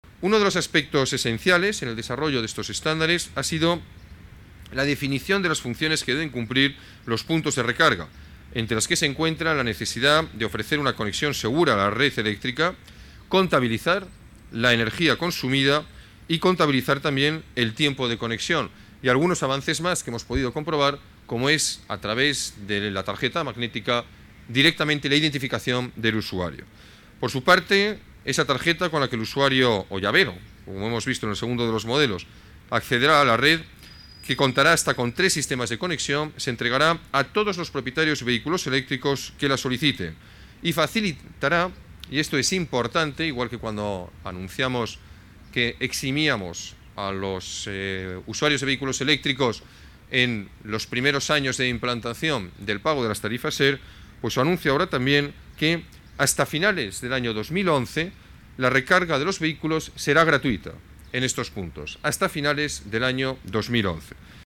Más archivos multimedia Declaraciones alcalde, Alberto Ruiz-Gallardón: oportunidad puntos recarga coche eléctrico Declaraciones alcalde, Alberto Ruiz-Gallardón: funciones puntos recarga coche eléctrico